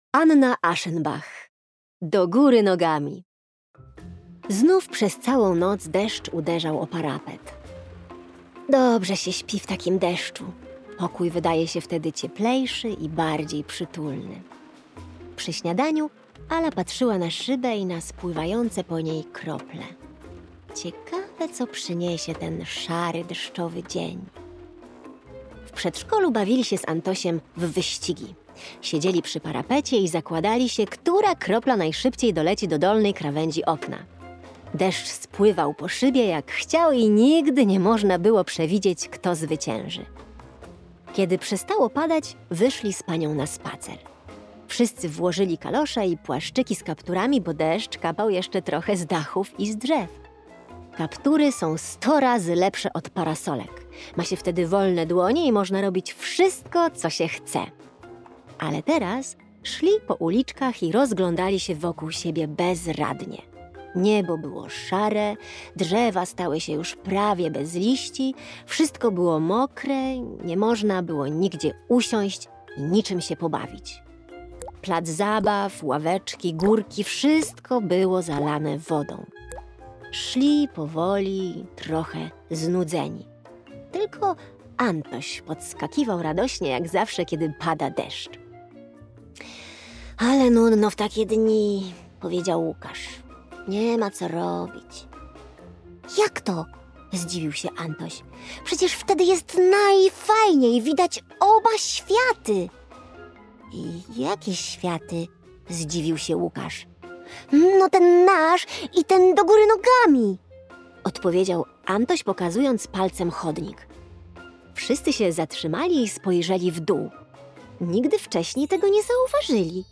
opowiadanie „Do góry nogami” - EDURANGA